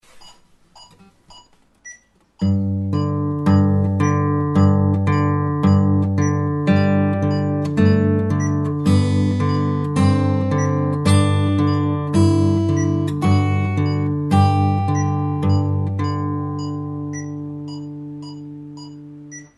Vi fortsätter genom att spela en G-dur skala över den här enkla basgången.
Fingerstyle 2.mp3